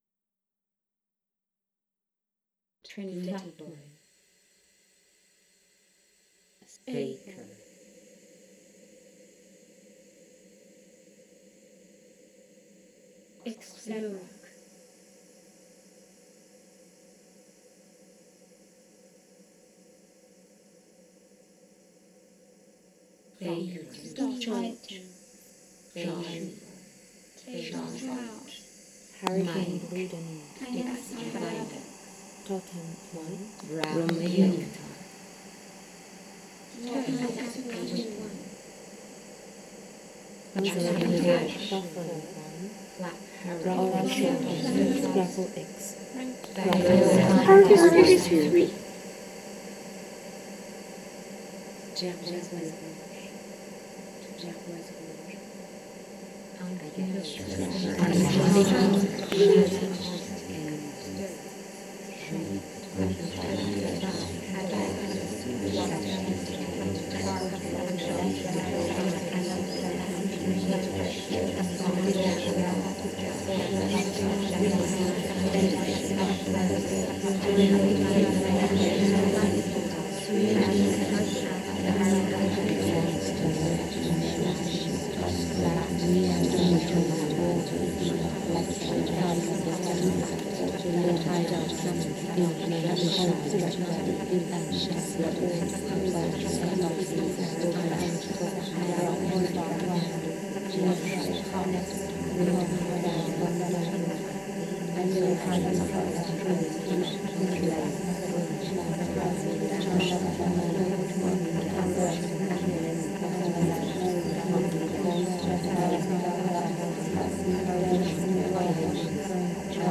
Installation sonore immersive et performance
Our Things est un projet immersif de création sonore retraçant l’histoire et la géographie des explosions nucléaires sur la planète depuis 1945. Il s’appuie sur un dispositif de sonification et de spatialisation, traduisant les différentes caractéristiques de ces explosions.
Vidéo à écouter au casque (spatialisation sonore binaurale)